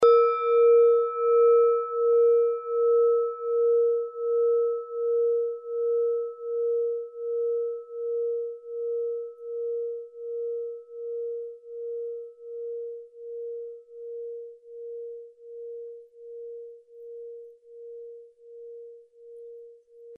Klangschale Nepal Nr.25
Klangschale-Gewicht: 1010g
Klangschale-Durchmesser: 14,2cm
(Ermittelt mit dem Filzklöppel)
klangschale-nepal-25.mp3